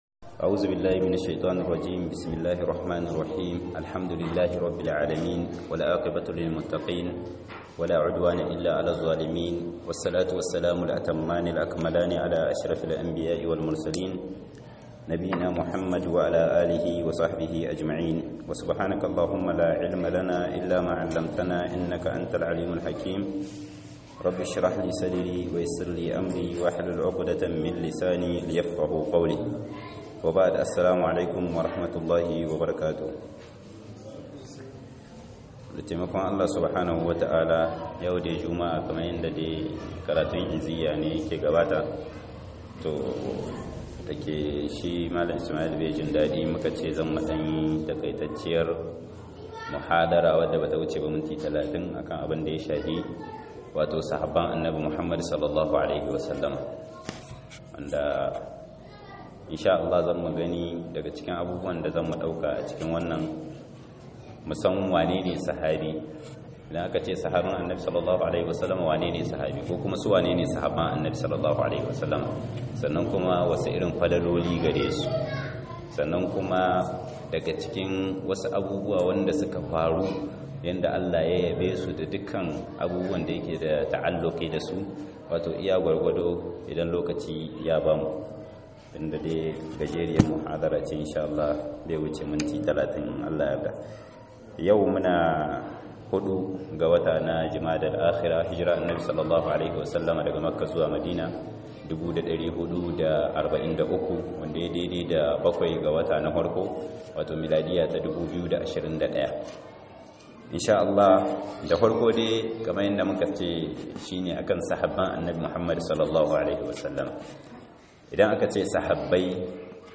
MUHADARA, SAHABAI - 2022-01-07_18'34'17' - MUHADARA